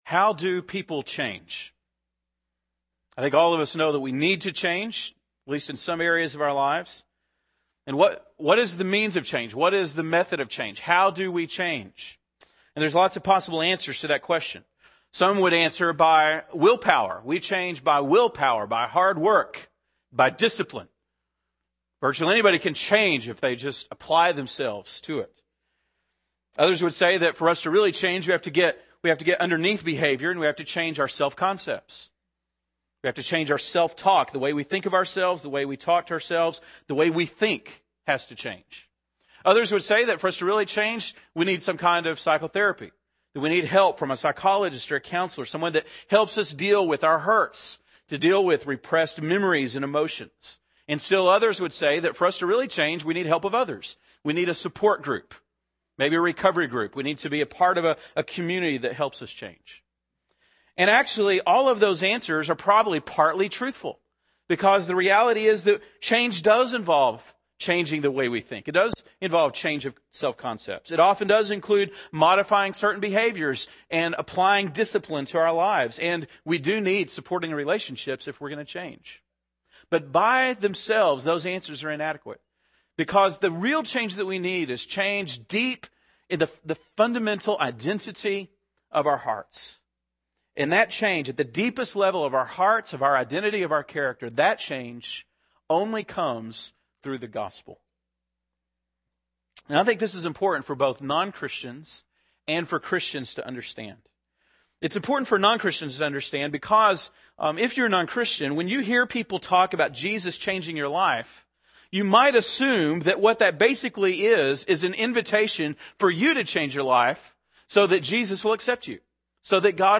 April 19, 2009 (Sunday Morning)